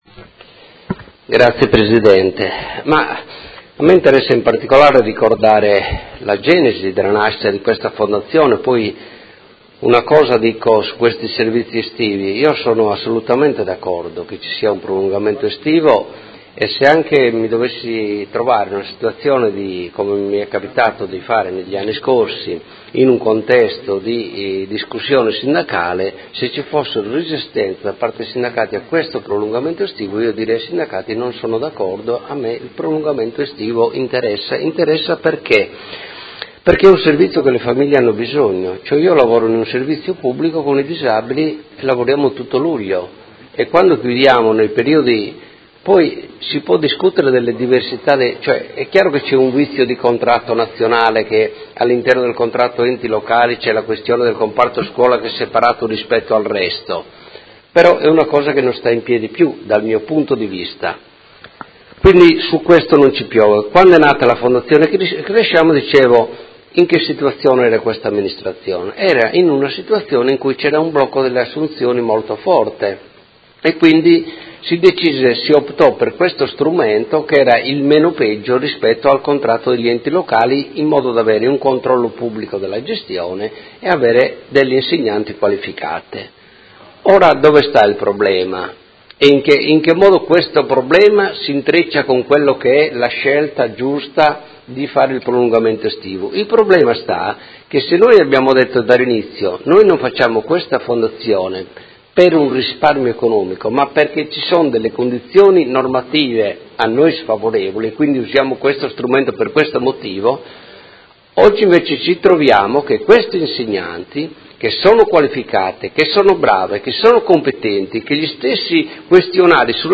Marco Cugusi — Sito Audio Consiglio Comunale
Seduta del 25/05/2017 Interrogazione dei Consiglieri Scardozzi, Bussetti e Rabboni (M5S) avente per oggetto: Servizio estivo nidi e scuola d’infanzia.